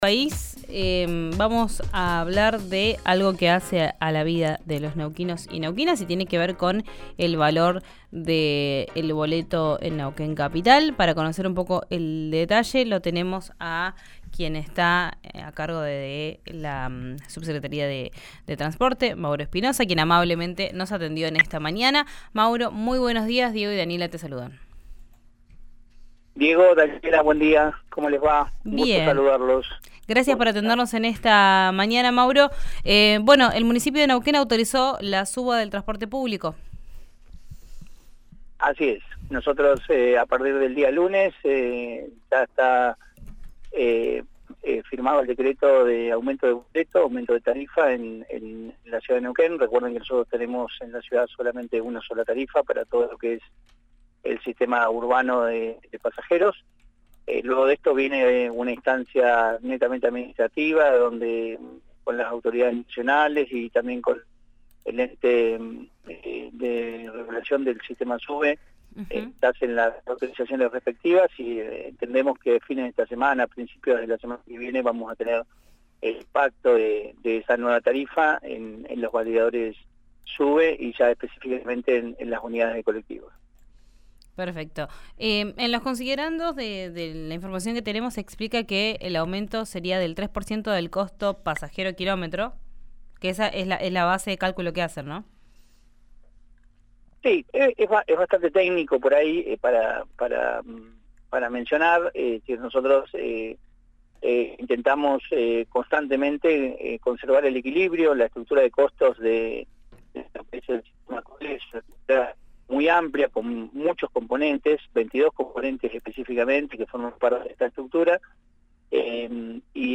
Escuchá al subsecretario de Transporte de Neuquén capital, Mauro Espinoza, en Vos al Aire por RÍO NEGRO RADIO